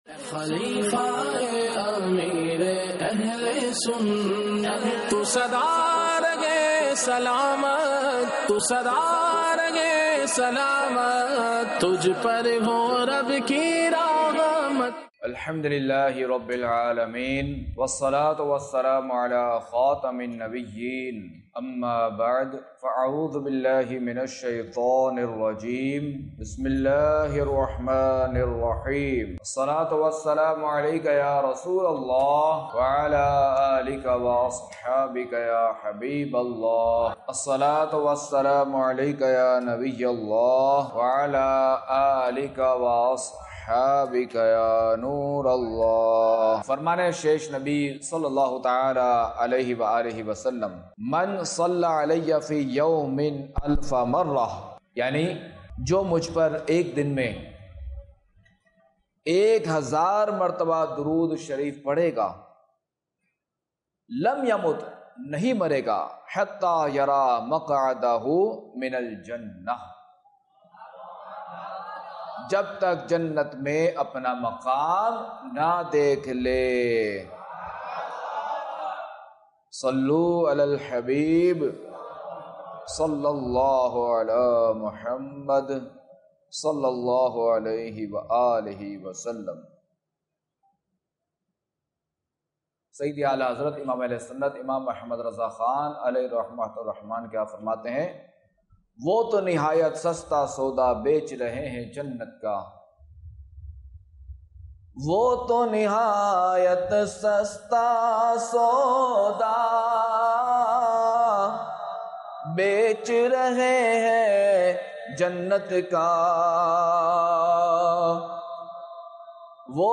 بیانات - صحابہ کرام علیہم الرضوان اور اطاعتِ رسول صلی اللہ علیہ وسلم